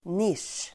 Uttal: [nisj:]